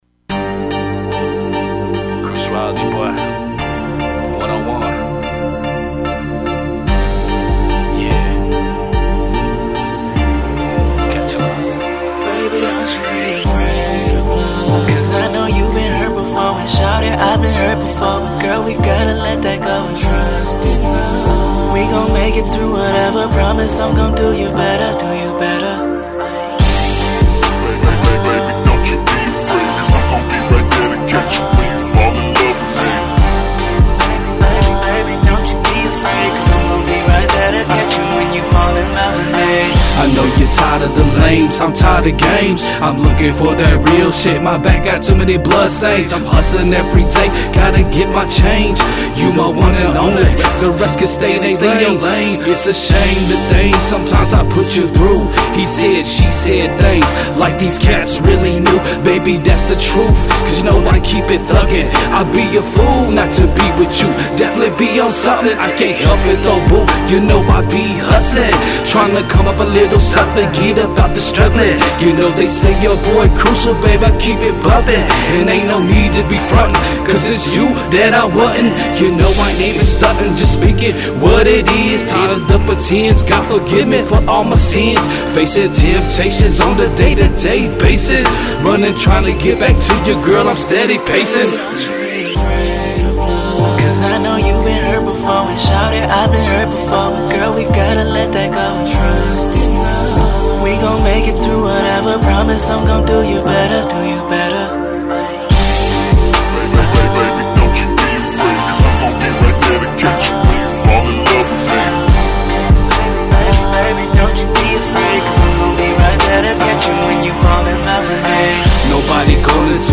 A student of Hip-Hop/Rap-music from an extremely young age